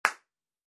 Звуки хлопков
Одинокий хлопок ладонью - Вариант 2